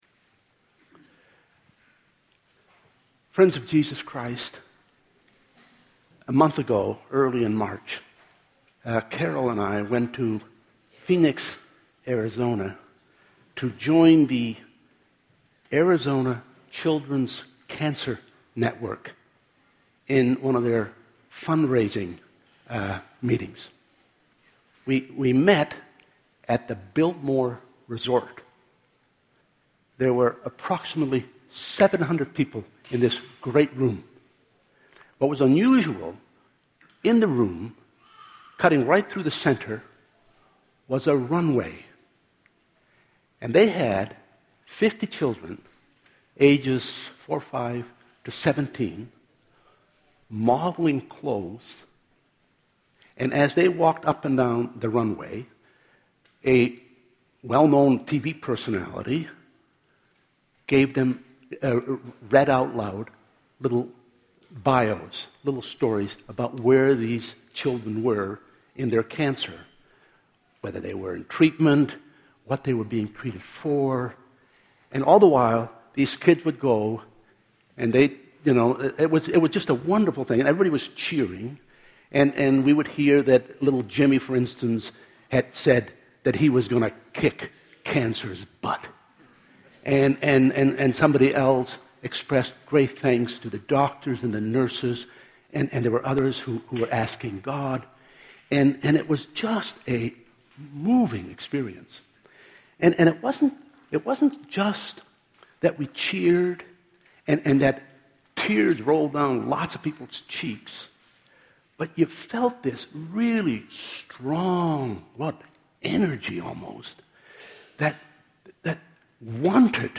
2014 Sermons Hope of Glory Play Episode Pause Episode Mute/Unmute Episode Rewind 10 Seconds 1x Fast Forward 30 seconds 00:00 / Subscribe Share RSS Feed Share Link Embed Download file | Play in new window